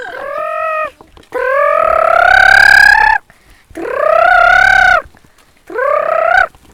동물소리흉내.ogg